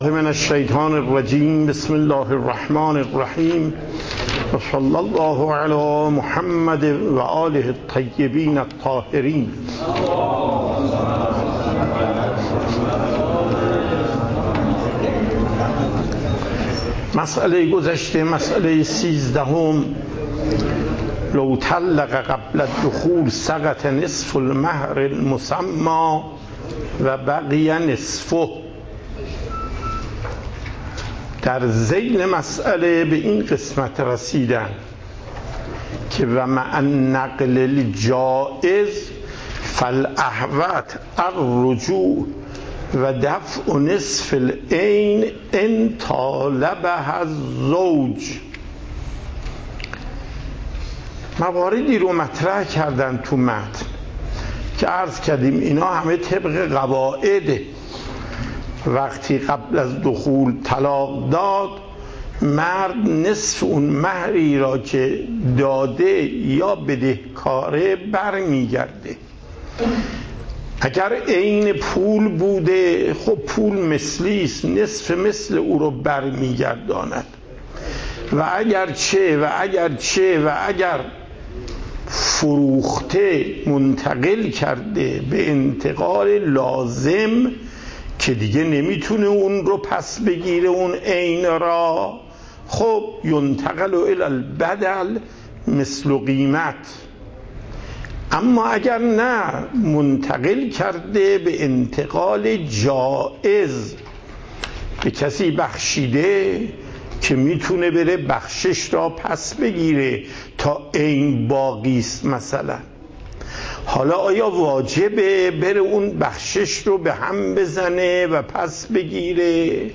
پخش زنده صوت درس + دریافت صوت و تقریر درس